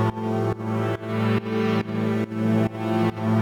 Index of /musicradar/sidechained-samples/140bpm
GnS_Pad-MiscB1:4_140-A.wav